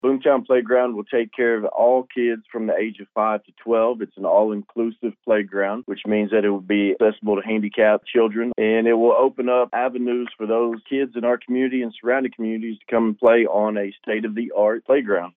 commentary